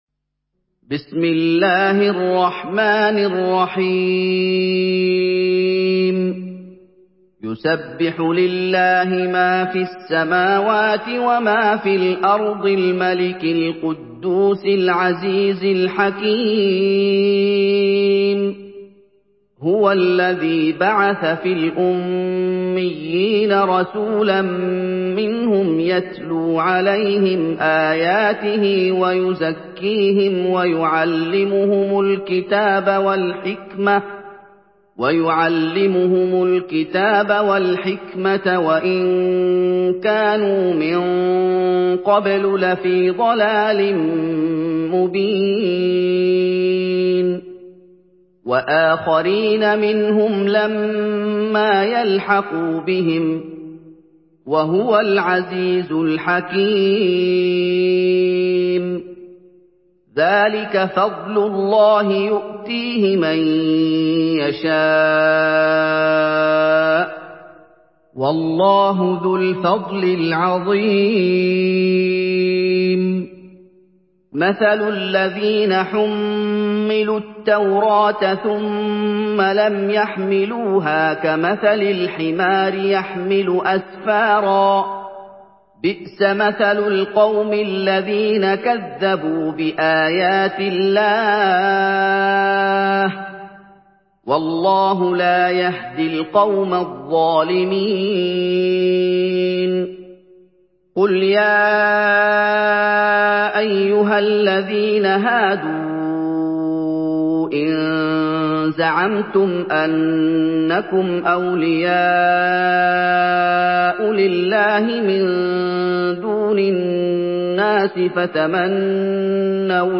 Surah Cüma MP3 by Muhammad Ayoub in Hafs An Asim narration.
Murattal Hafs An Asim